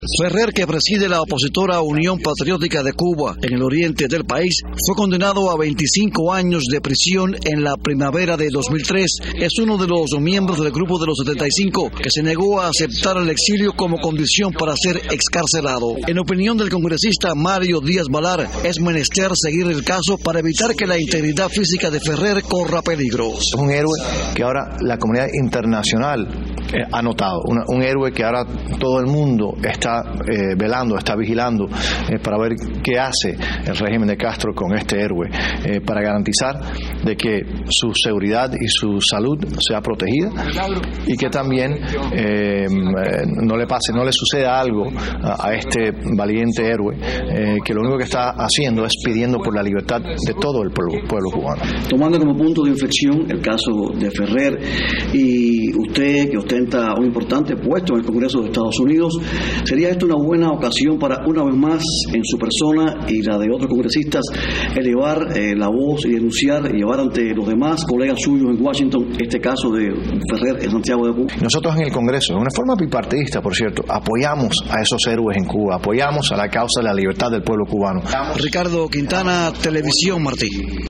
Declaraciones del congresista Mario Díaz-Balart